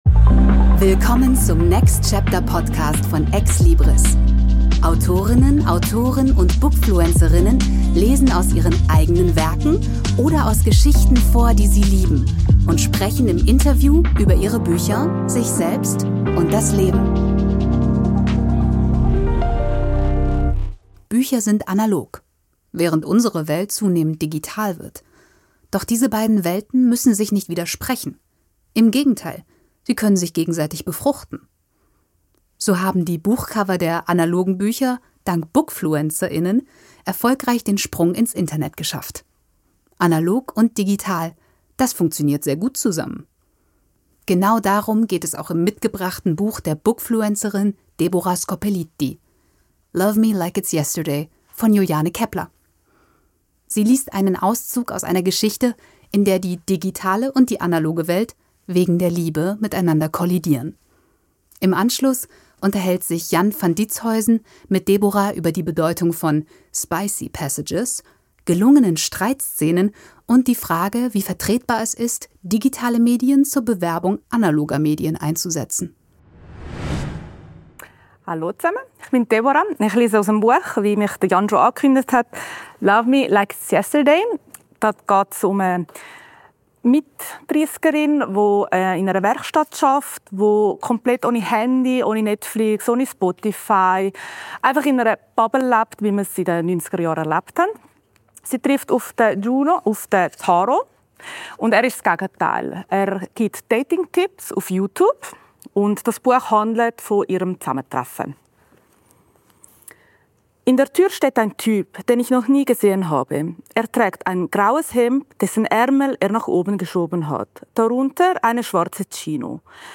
Sie liest einen Auszug aus einer Geschichte, in der die digitale und die analoge Welt wegen der Liebe miteinander kollidieren.